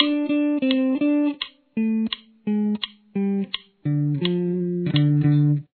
Intro Riff
riff_1_slow.mp3